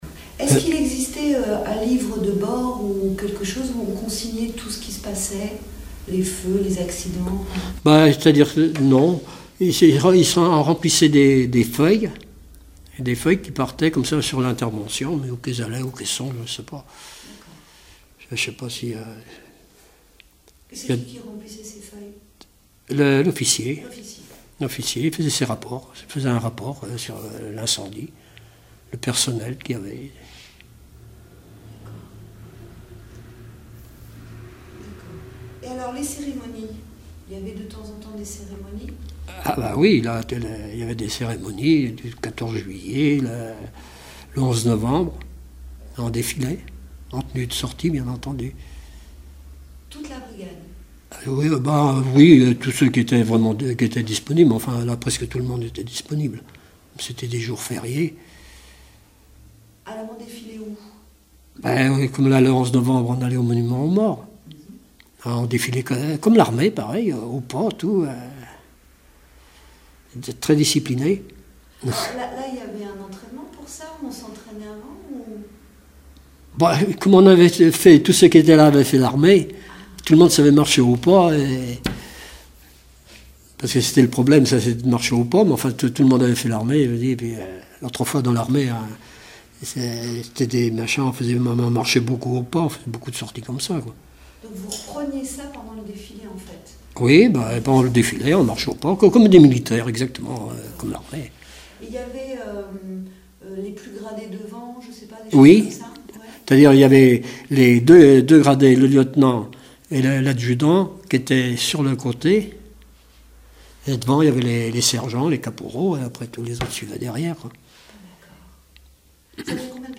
Témoignages d'un ancien sapeur-pompier
Catégorie Témoignage